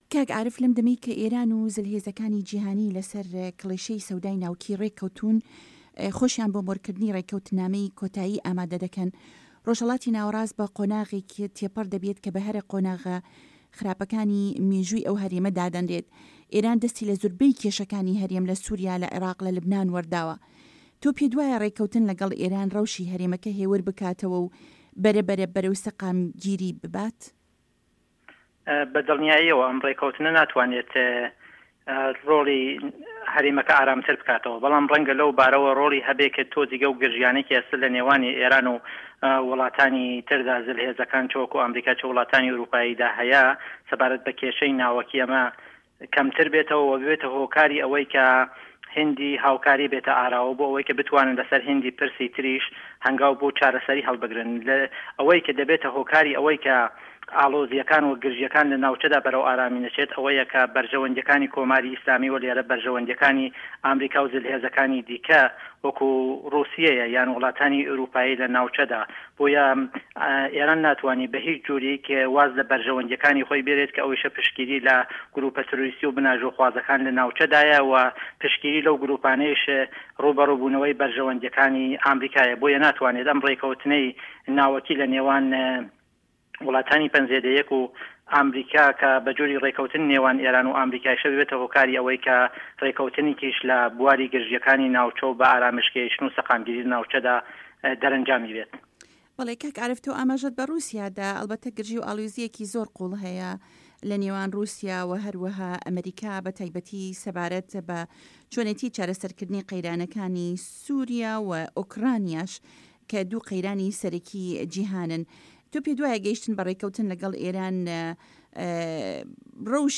گفتوگۆ